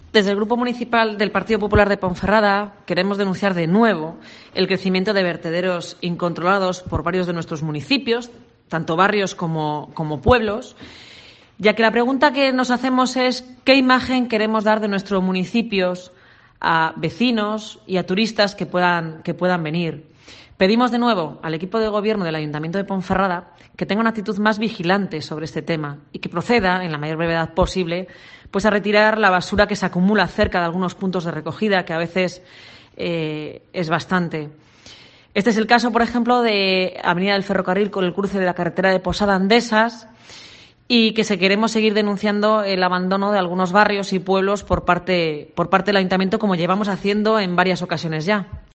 AUDIO: Así lo ha puesto de manifiesto la concejala Rosa Luna